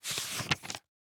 ES_Book Paperback 15 - SFX Producer.wav